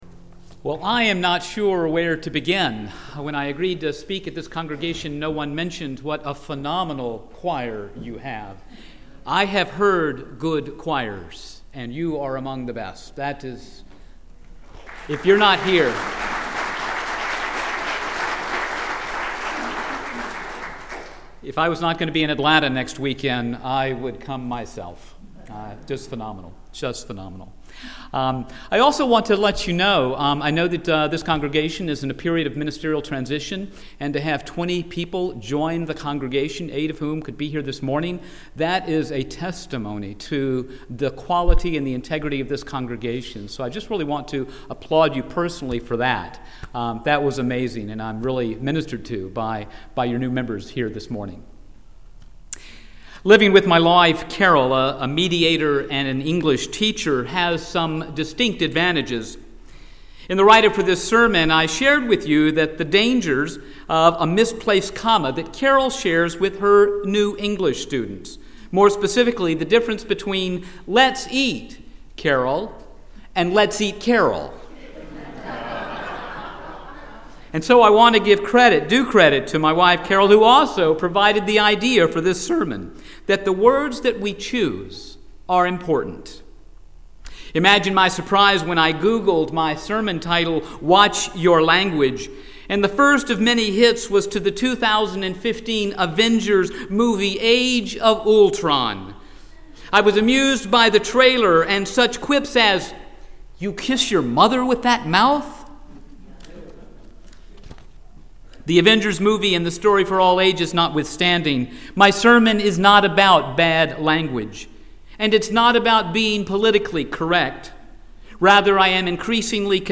My sermon is NOT about compassionate communication, dirty words, or political correctness.